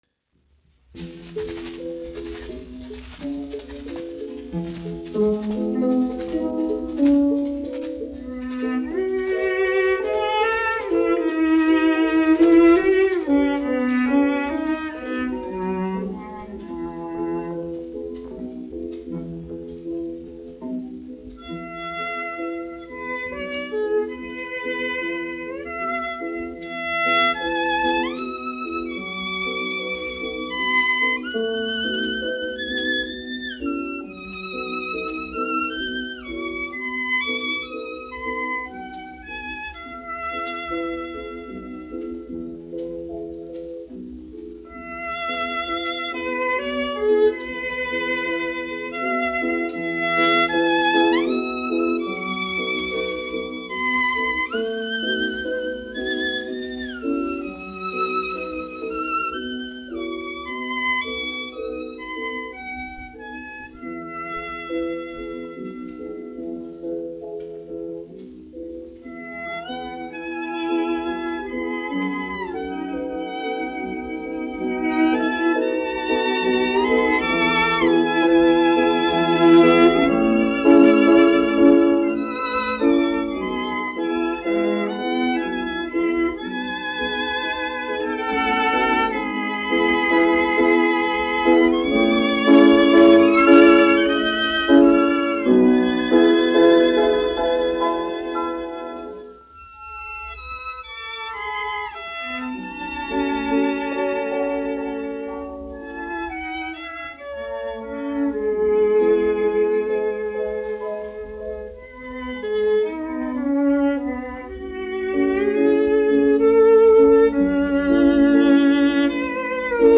1 skpl. : analogs, 78 apgr/min, mono ; 25 cm
Klavieru trio, aranžējumi
Latvijas vēsturiskie šellaka skaņuplašu ieraksti (Kolekcija)